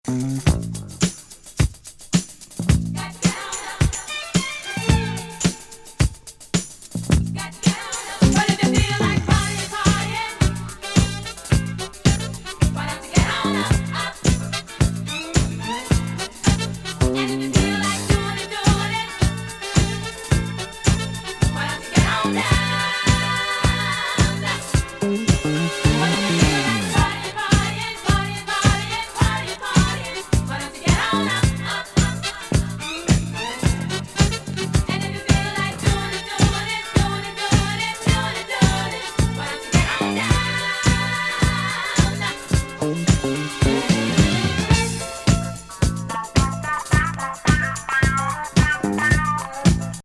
ヘビーめボトムなミディアム黒グルーブ・ファンキー・ディスコ！